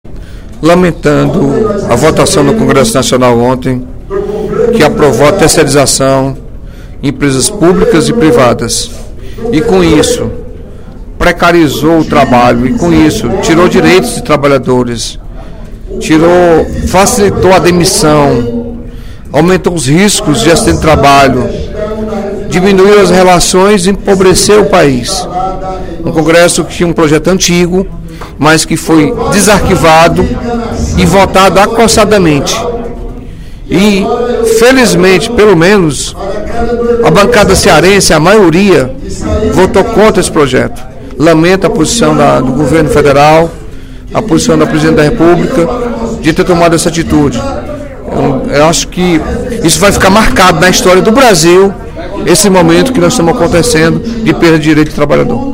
O deputado Carlos Felipe (PCdoB) lamentou, no primeiro expediente da sessão plenária desta quinta-feira (09/04), a aprovação, pela Câmara dos Deputados, do projeto de lei nº 4330/04, que dispõe sobre a contratação de serviço terceirizado de diversas categorias para instituições públicas e privadas.